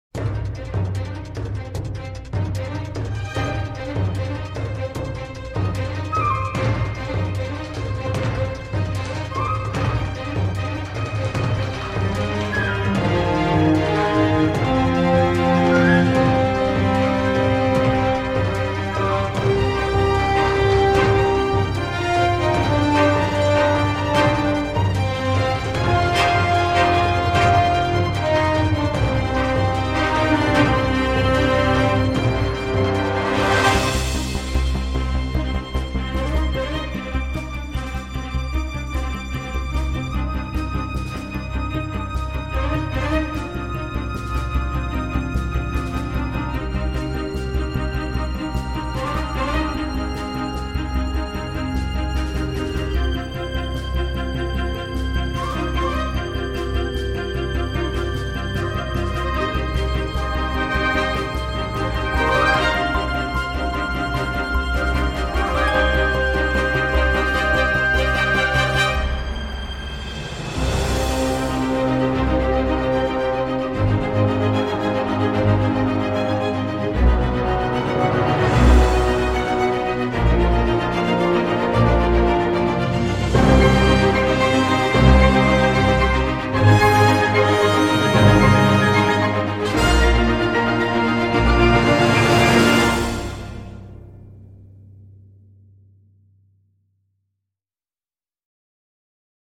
Et le son sans ampleur et assez cheap n’arrange rien.
Les pistes s’enchaînent et se ressemblent.